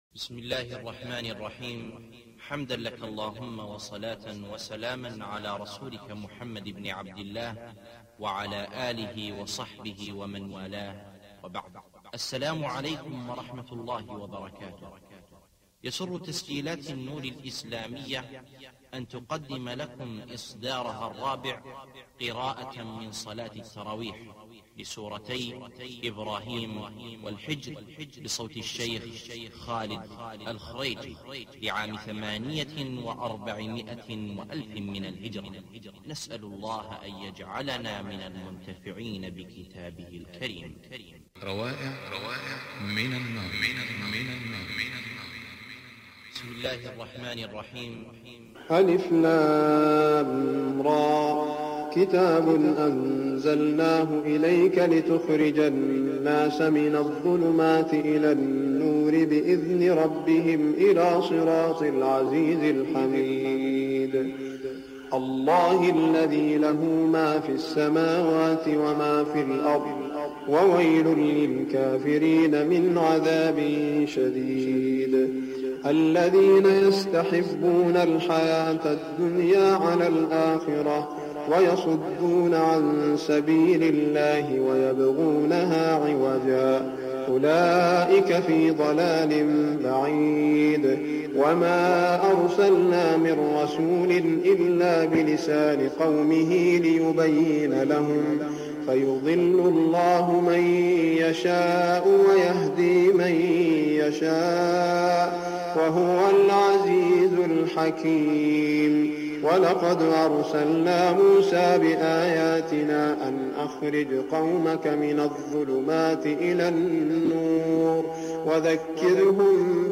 تلاوة رائعة لسورتي إبراهيم والحجر لعام 1408هـ صوتي ومرئي بجودة عالية